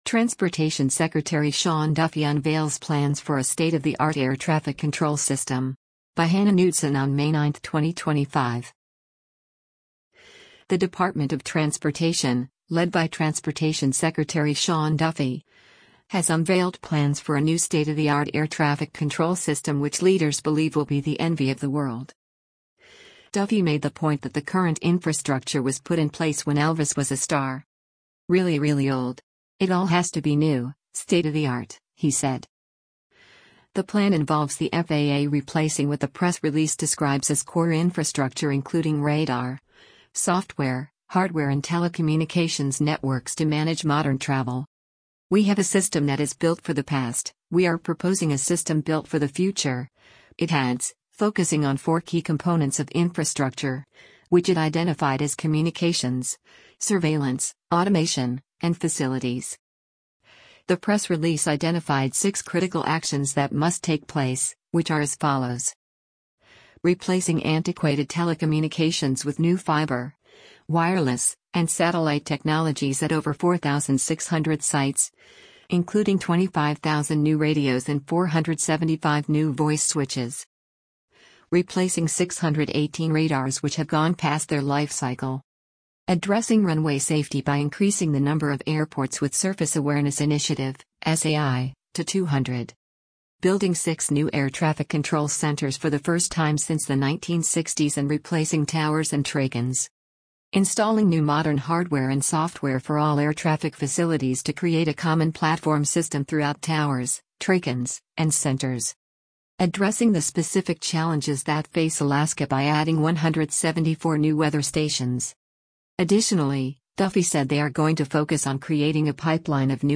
Sean Duffy, US secretary of transportation, during a news conference at the US Department